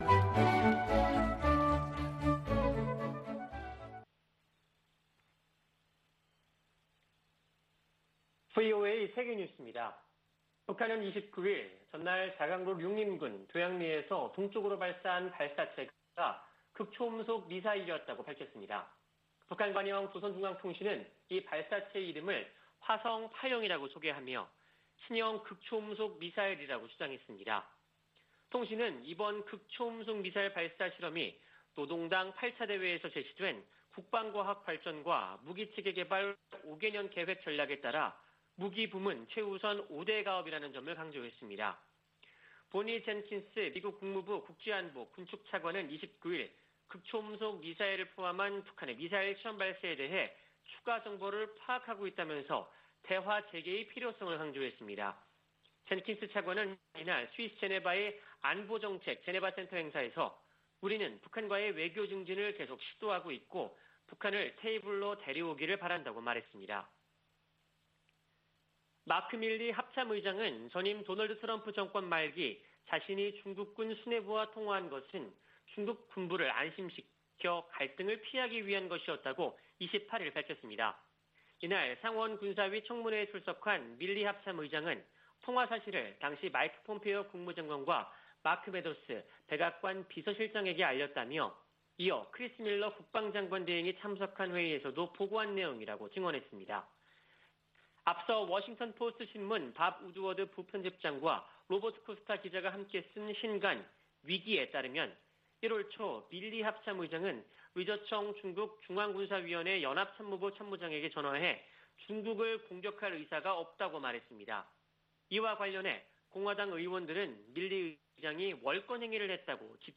VOA 한국어 아침 뉴스 프로그램 '워싱턴 뉴스 광장' 2021년 9월 26일 방송입니다. 미국 국무부 고위 관리가 북한의 최근 탄도미사일 발사들을 우려하며 규탄한다고 밝혔습니다. 북한이 유화적 담화를 내놓은 뒤 미사일을 발사한 것은 대미 협상에서 우위를 확보하기 위한 것으로 미 전직 관리들은 분석했습니다. 국제사회는 북한의 탄도미사일 발사를 규탄하고, 불법 행위를 멈출 것을 촉구했습니다.